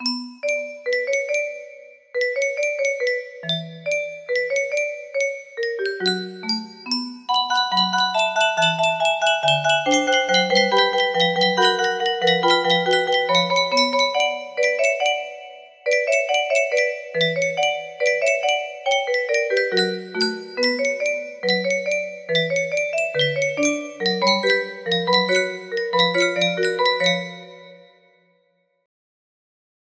Full range 60
Ethereal Workshop Cover :}